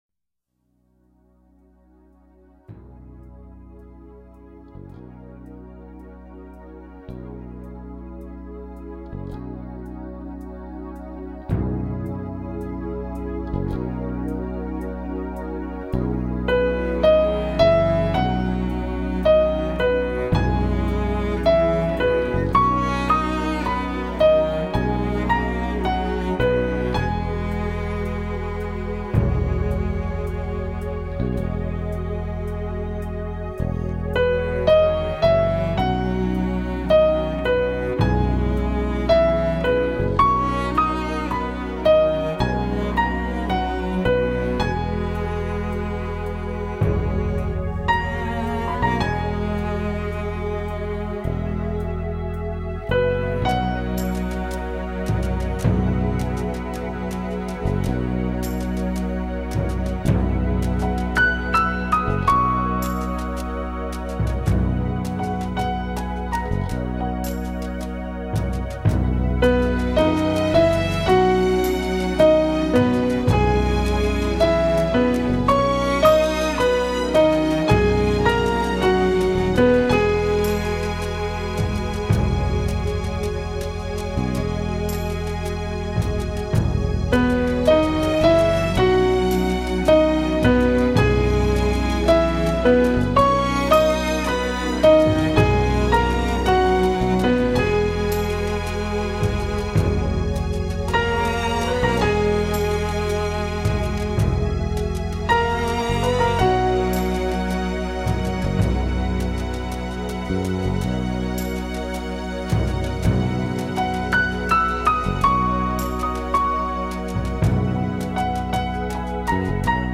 有大型乐团管弦乐，亦有清幽单簧管、长笛、双簧管和小提琴协奏、更有女声咏唱。